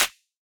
menu_select.ogg